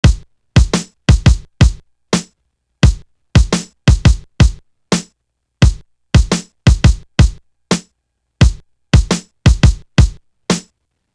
Going Pop Drum.wav